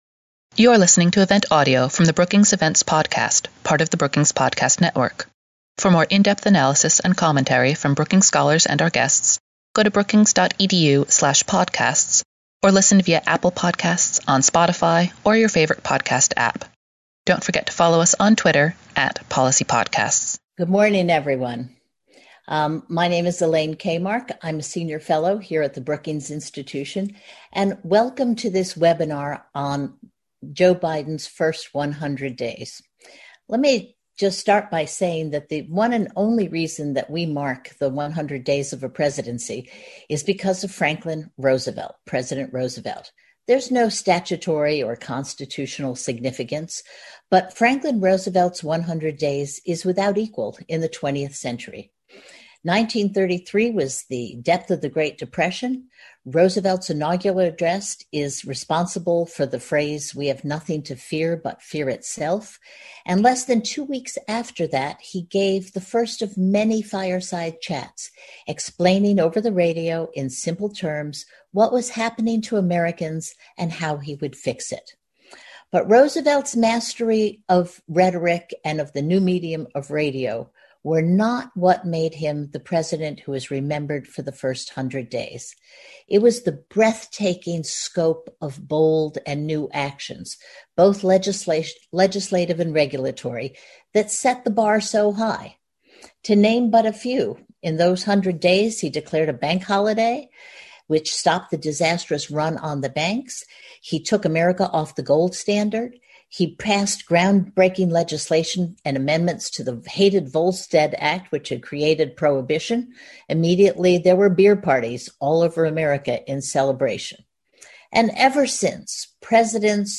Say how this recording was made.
On May 3, Brookings will host a webinar to reflect on the first 100 days of the Biden presidency and look forward to what policies may come in the future.